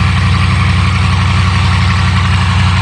sound / acf_engines / w16large.wav